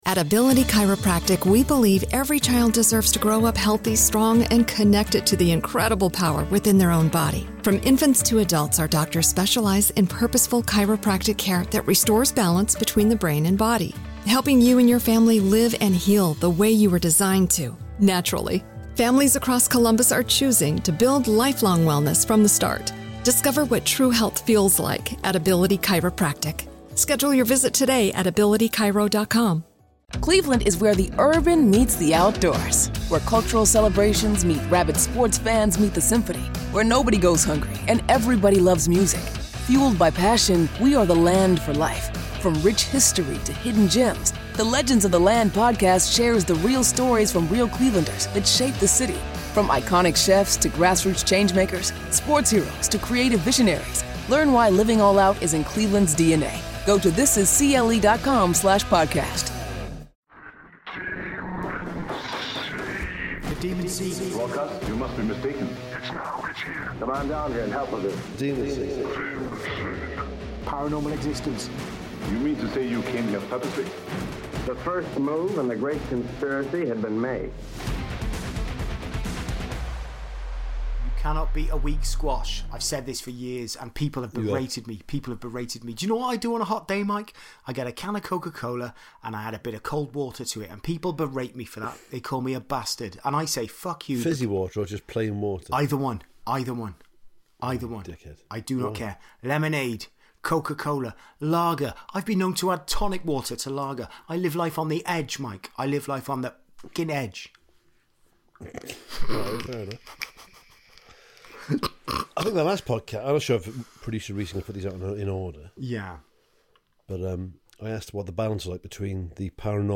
With double the accents, nonsense and fart noises, and half the paranormal.